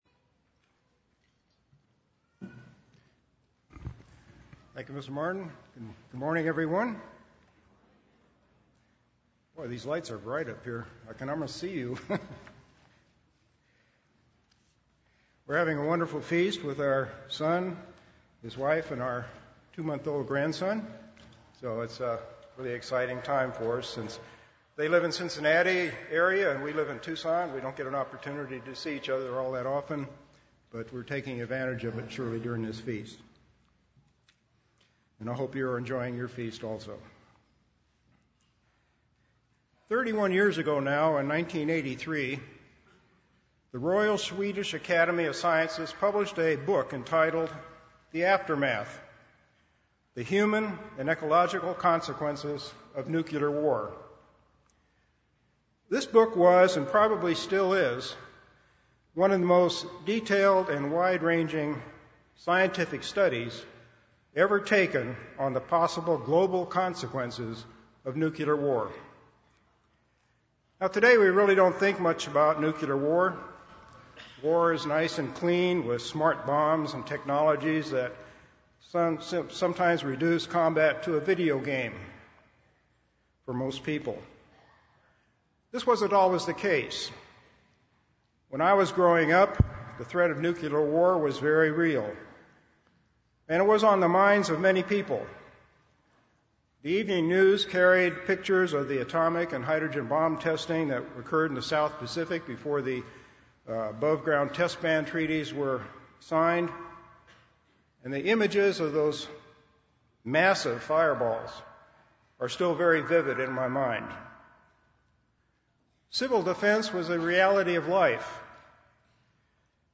This sermon was given at the Panama City Beach, Florida 2014 Feast site.